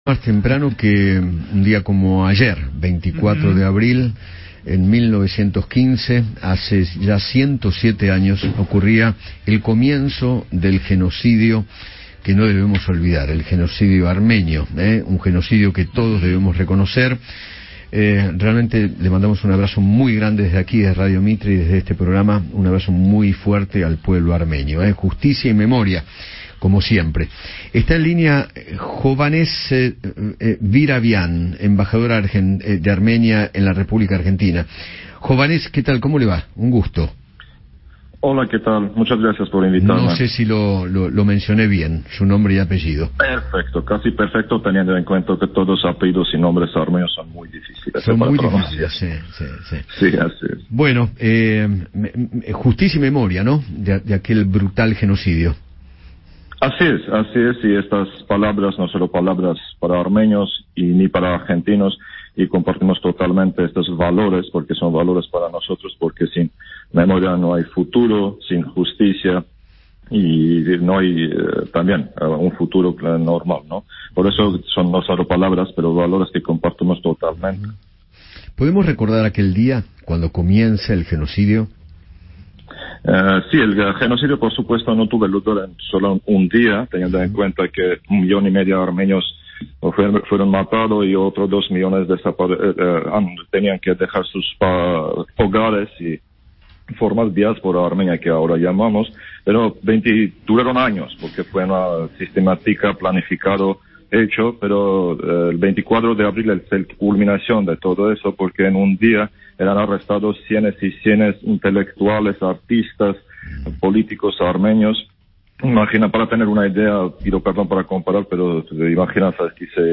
Eduardo Feinmann conversó con Hovhannes Virabyan, embajador de Armenia en Argentina, sobre el homenaje que realizaron en Ereván a las víctimas del genocidio armenio de 1915 bajo el Imperio otomano.